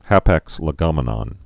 (hāpăks lĭ-gŏmə-nŏn)